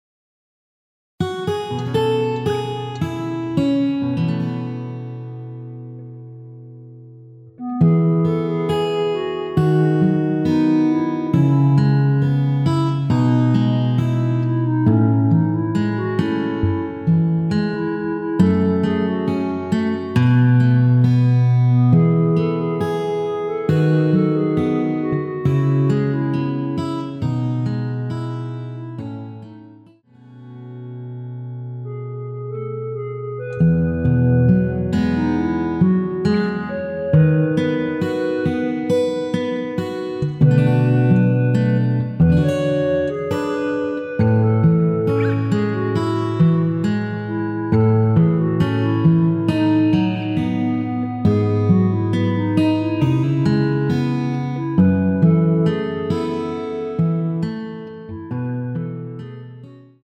원키 멜로디 포함된 MR입니다.
멜로디 MR이라고 합니다.
앞부분30초, 뒷부분30초씩 편집해서 올려 드리고 있습니다.